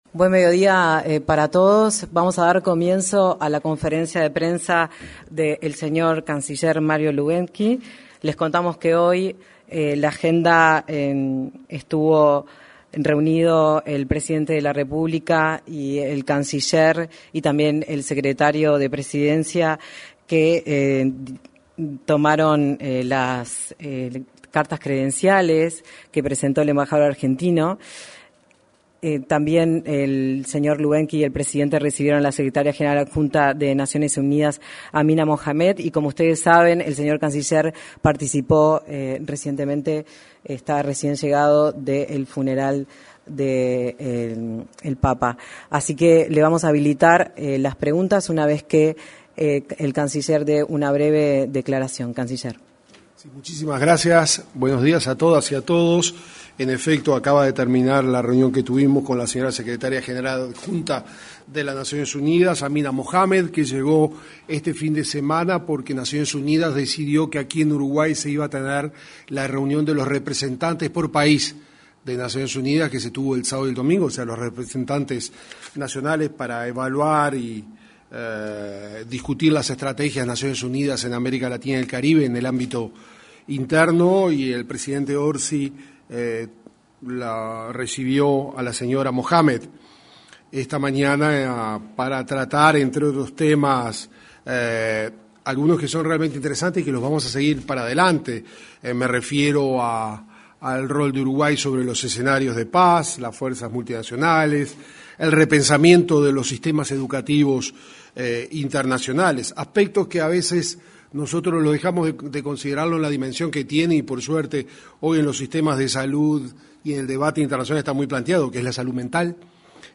Conferencia de prensa del canciller de la República, Mario Lubetkin
Conferencia de prensa del canciller de la República, Mario Lubetkin 28/04/2025 Compartir Facebook X Copiar enlace WhatsApp LinkedIn En el día de hoy, 28 de abril, el canciller Mario Lubetkin informó en conferencia de prensa sobre temas de actualidad de la gestión internacional, en Torre Ejecutiva.